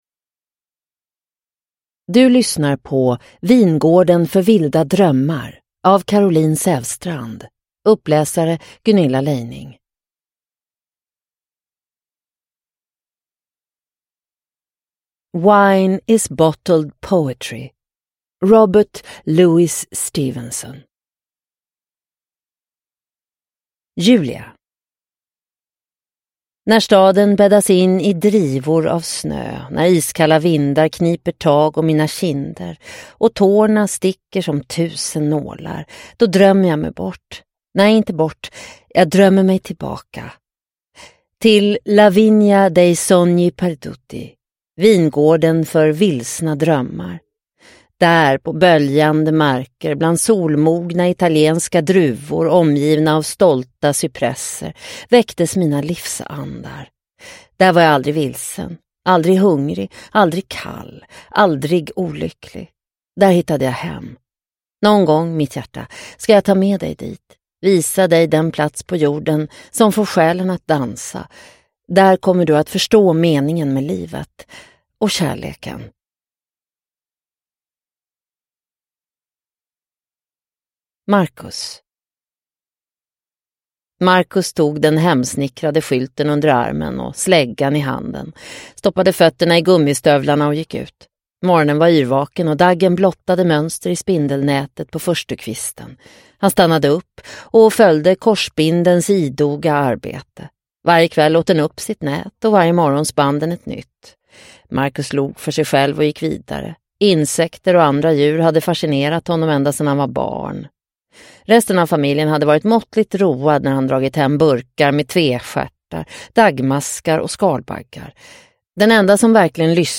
Vingården för vilda drömmar – Ljudbok – Laddas ner